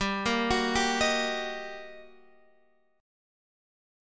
GmM7#5 chord